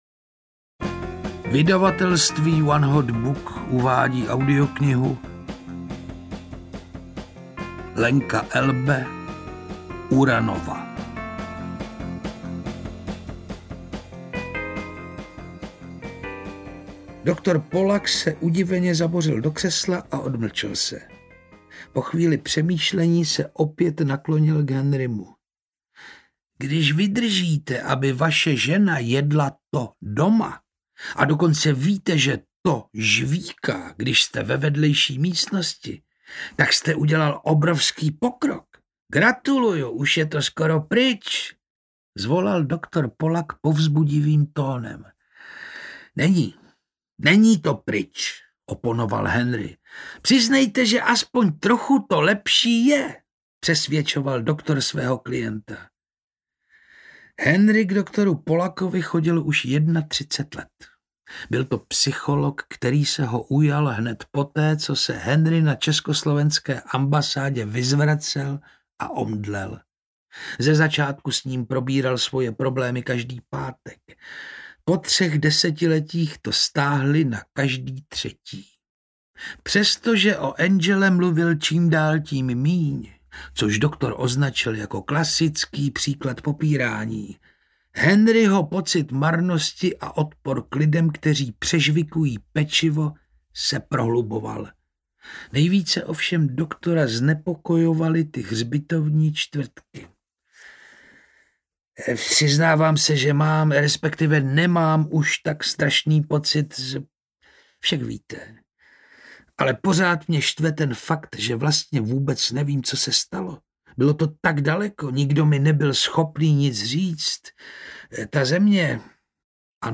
URaNovA audiokniha
Ukázka z knihy